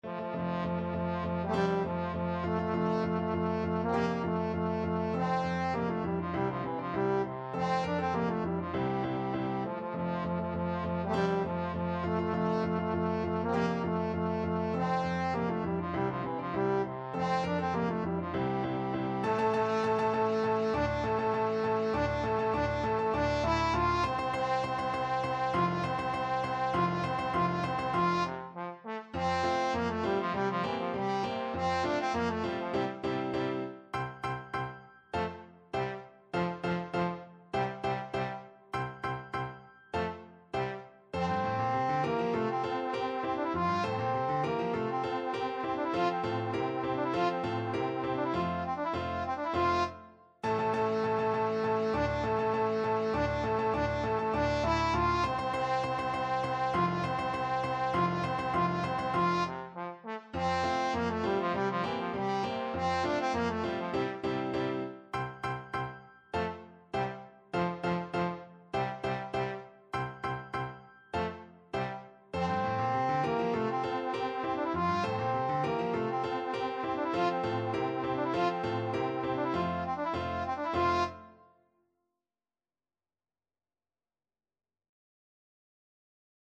Trombone
2/2 (View more 2/2 Music)
F major (Sounding Pitch) (View more F major Music for Trombone )
March = c.100
Classical (View more Classical Trombone Music)
yorckscher_marsch_TBNE.mp3